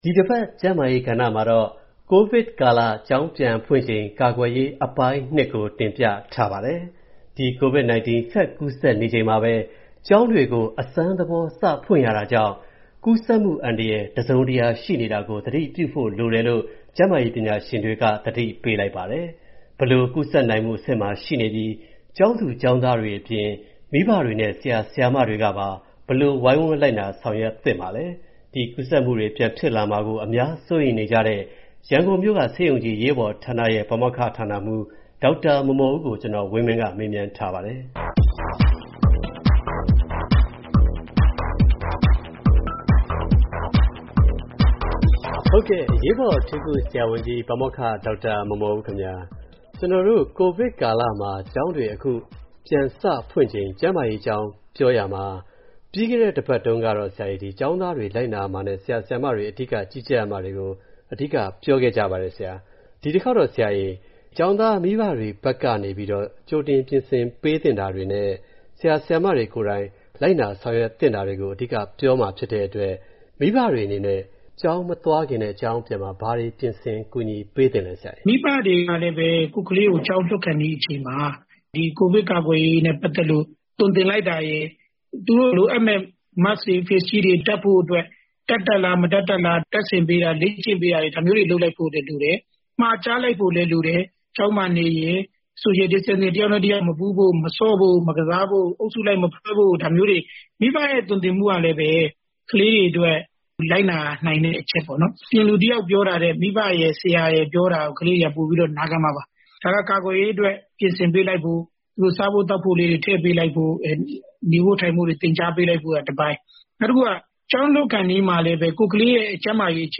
ဆက်သွယ်မေးမြန်း တင်ပြထားပါတယ်။